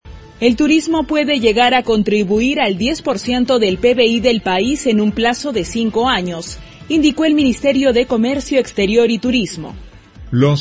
Titulares